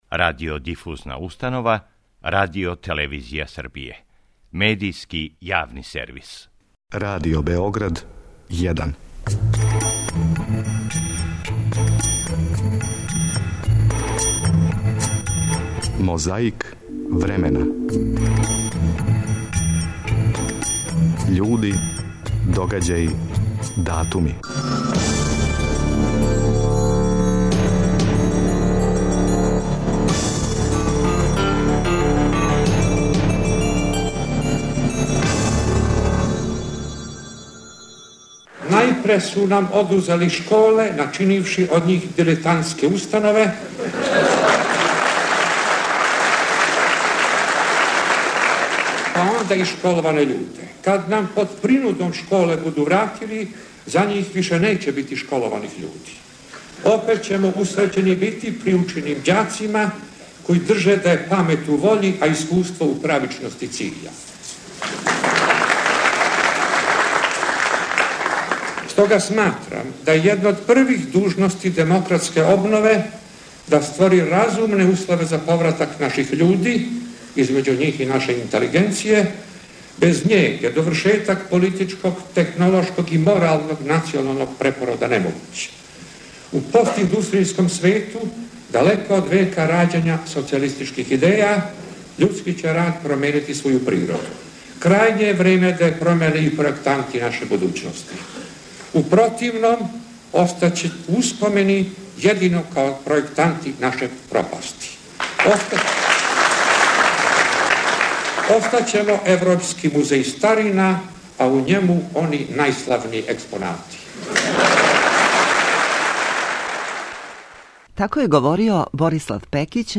Преко звучних коцкица враћамо се у прошлост да чујемо како је говорио Борислав Пекић на оснивачкој скупштини Демократске странке одржаној 3. фебруара 1990. године.
Технички, снимак није савршен, али је више него довољан да се сетимо.